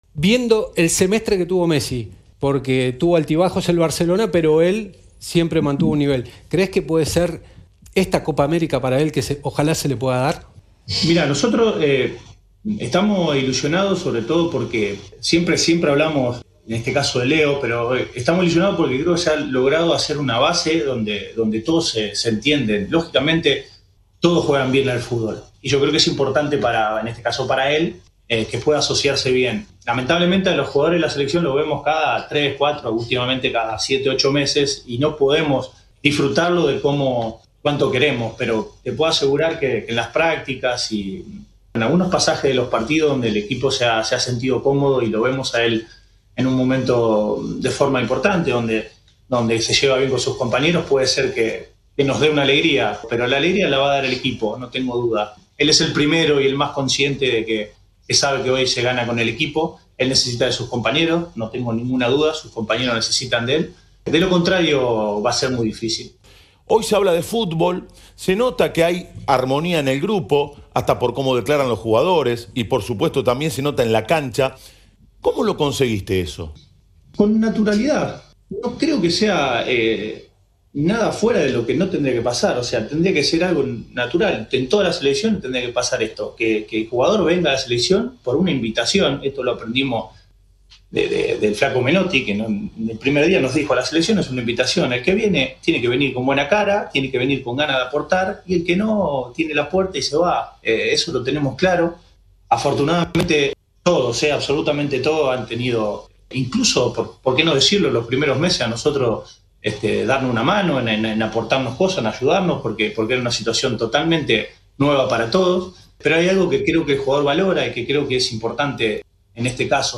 (Lionel Scaloni, DT de Argentina, en diálogo con ESPN)